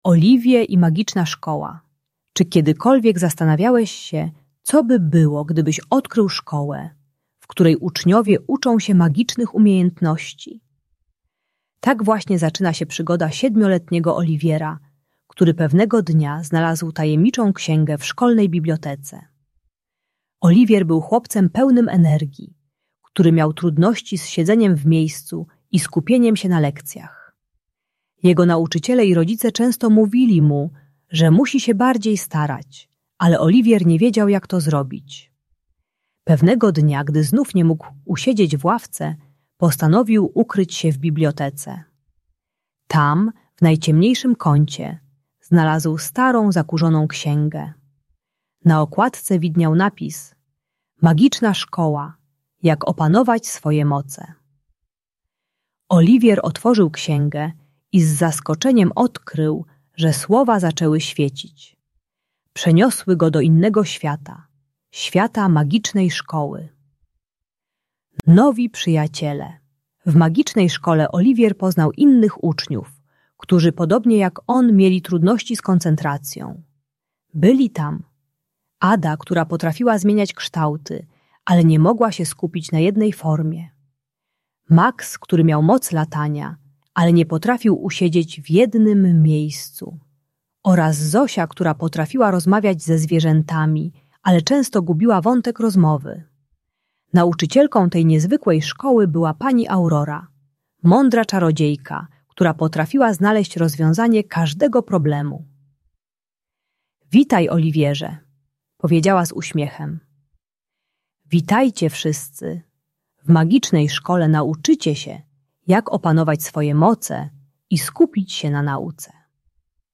Olivier i Magiczna Szkoła - Szkoła | Audiobajka